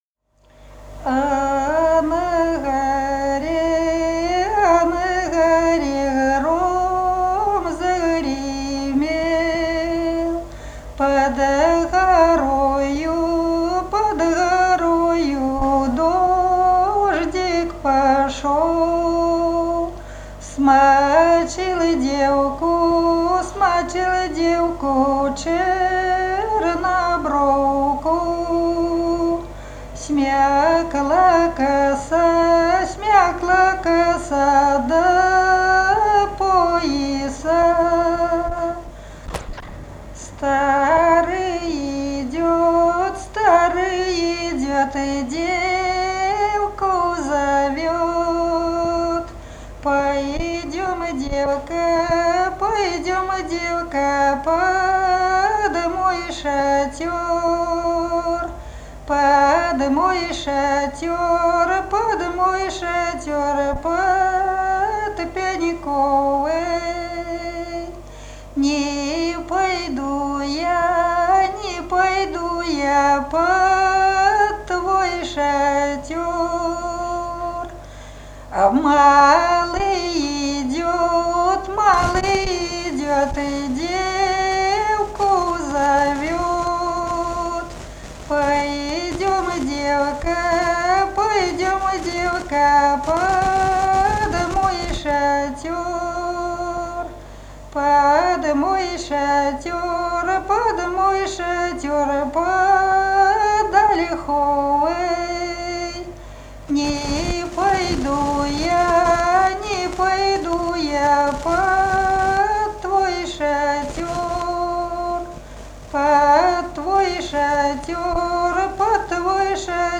Народные песни Смоленской области
«А на горе гром загремел» (апрельская).